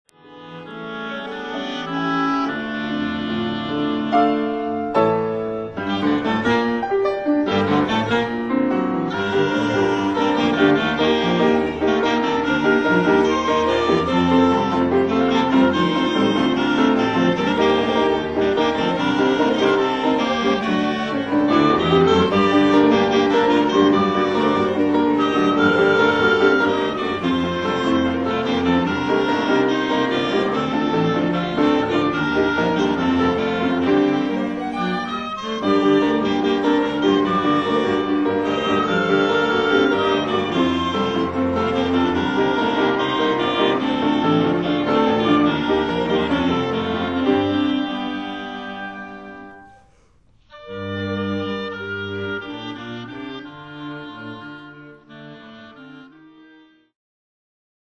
Flute、Oboe、Clarinet、Violin、Cello、Piano